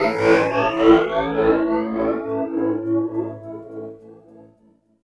18 GUIT 4 -R.wav